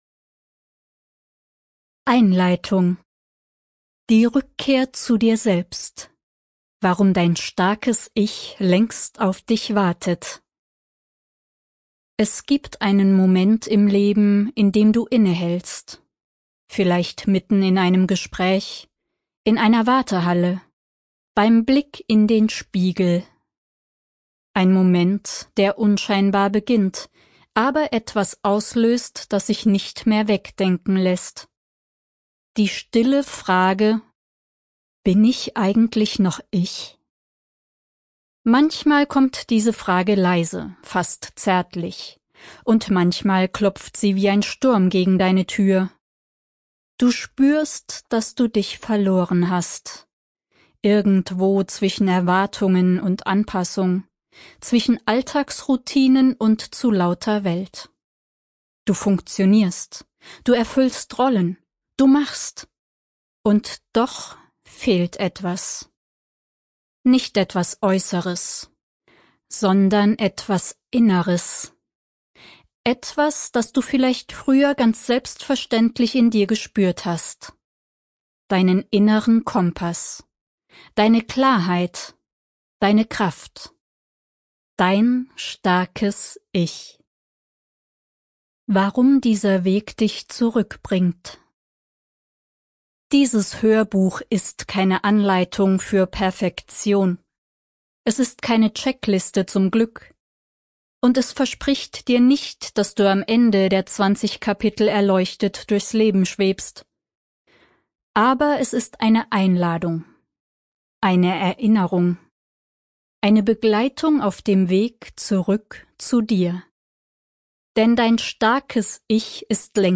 • Top-Studioqualität und professioneller Schnitt
Hörbuch